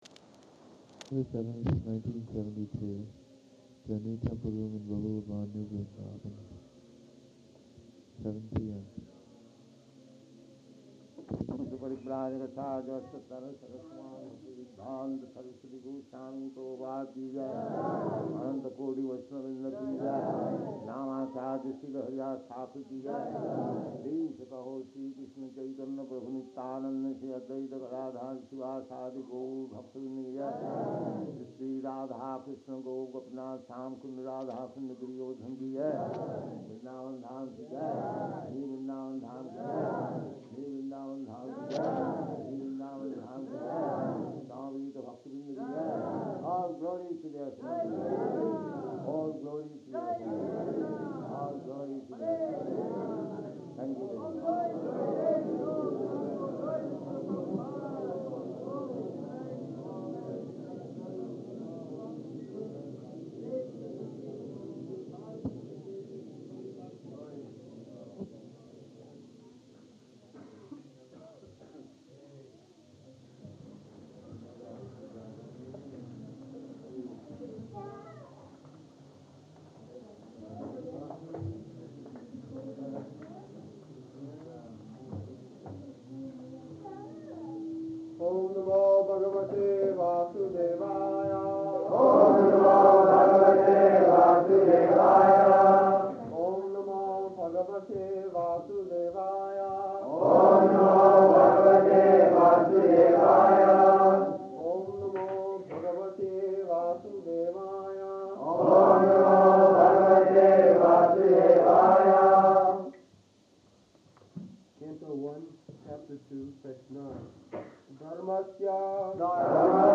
Srila Prabhupada Lectures | What is that religion | Srimad Bhagavatam 1-2-6 | Bombay – In Service of Srimad Bhagavatam™ – Podcast